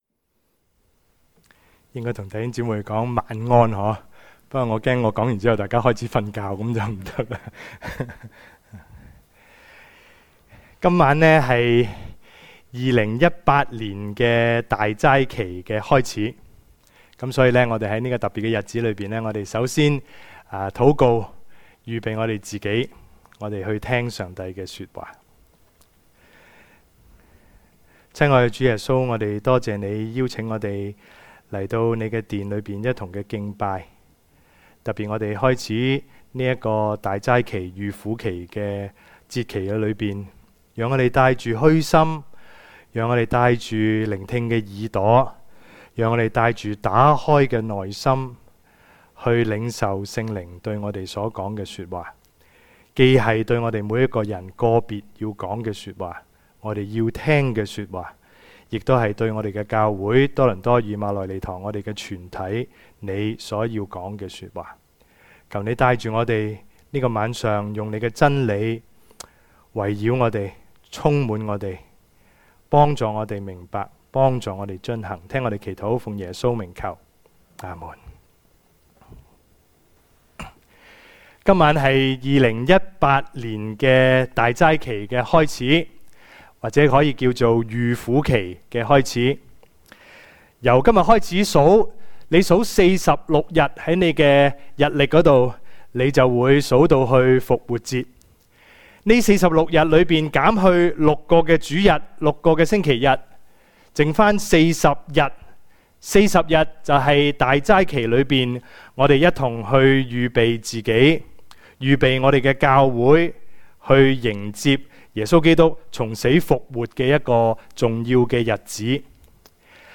Sermon / 講道錄音 – Page 67 – Toronto Emmanuel Church